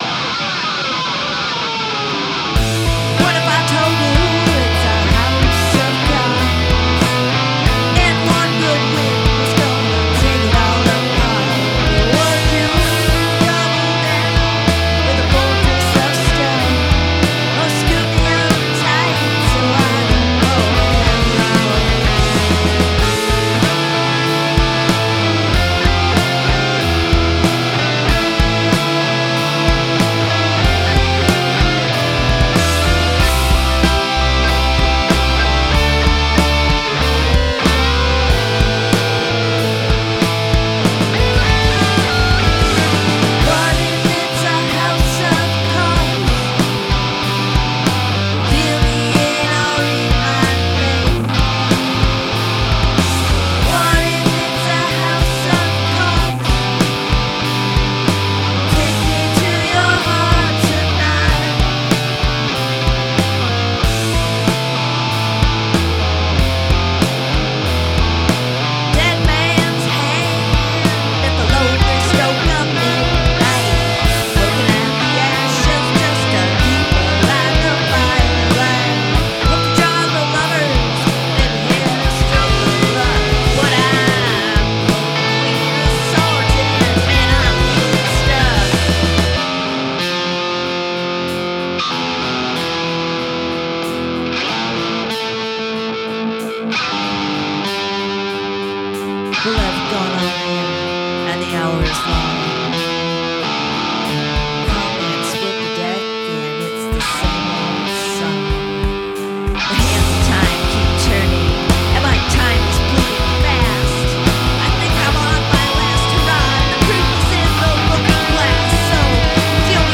Love that flip into 12/8 (or 6/8) time during the breakdown.
Whoa distortion screaming tone. Cool slide to open.